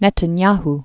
(nĕtn-yäh, nĕtän-), Benjamin or Binyamin Born 1949.